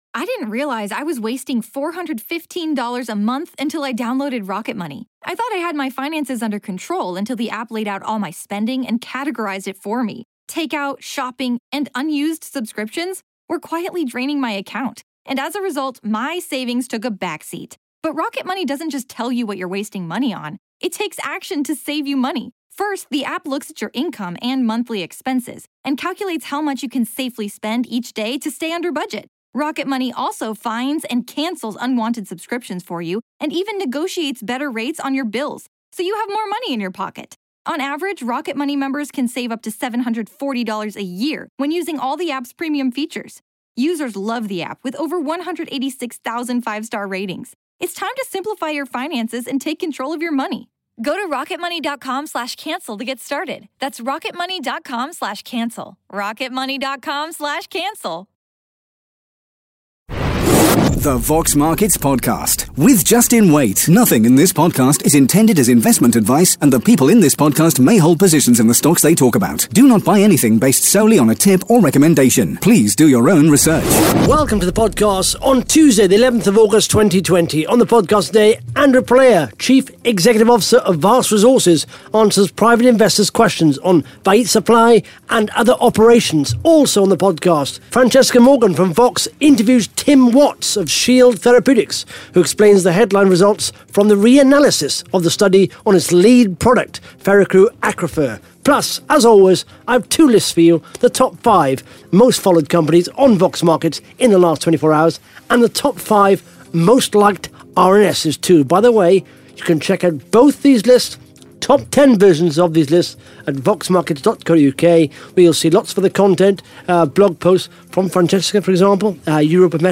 (Interview starts at 13 minutes 25 seconds) Plus the Top 5 Most Followed Companies & the Top 5 Most liked RNS’s on Vox Markets in the last 24 hours.